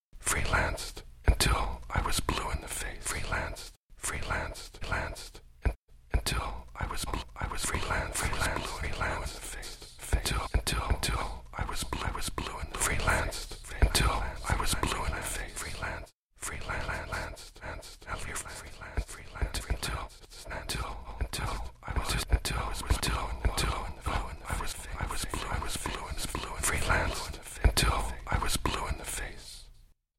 The American writer's voice is played back as a straight recitation coming from certain directions or moving around the audience
Audio Example: Paul Auster's voice cutted and spatialized
07_Loop_vocals.mp3